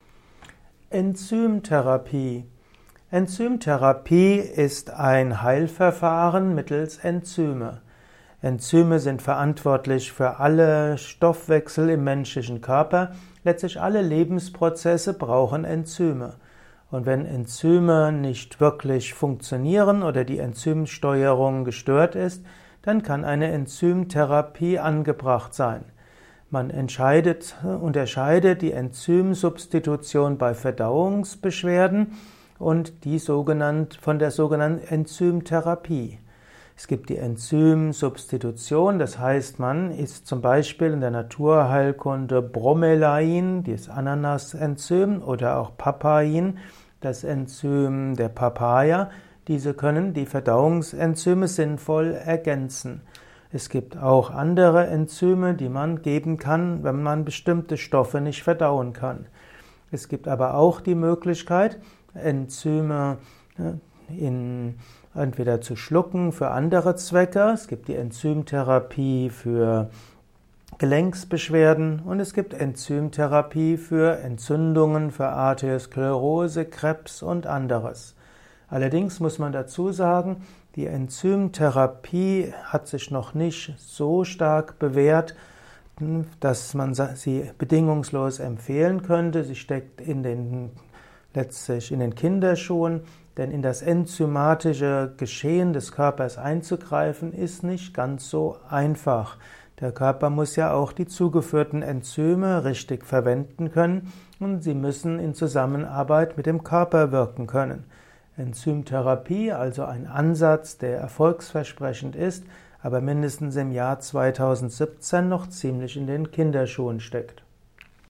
Ein Kurzvortrag zu dem Begriff Enzymtherapie